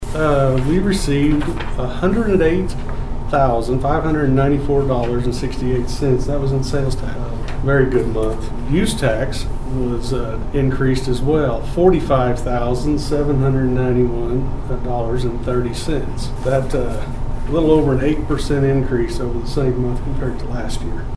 The Dewey City Council met for their second of two regular monthly meetings on Monday night in what was a relatively short meeting.
City Manager Kevin Trease shared his update on said reports.